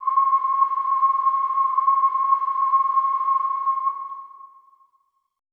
WHISTLE C -R.wav